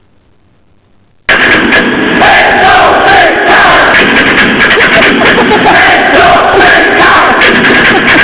LOTD in Graz